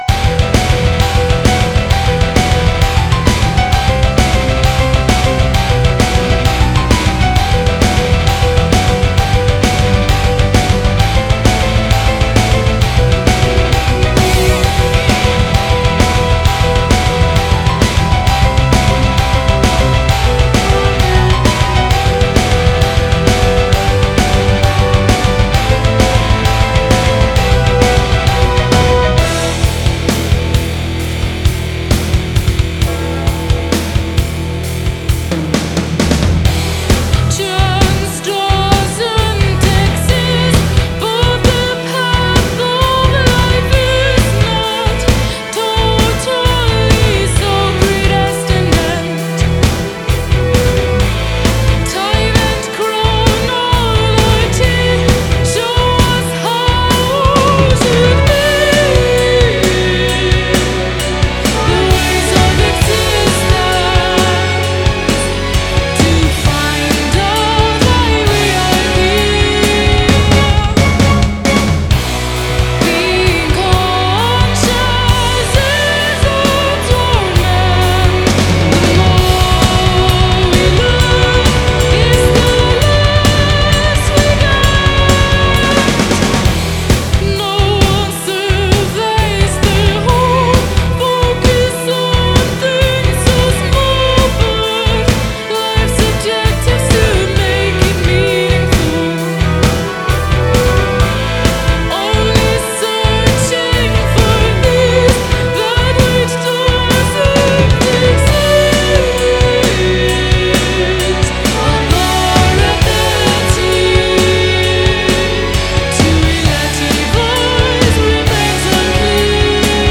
Metal Rock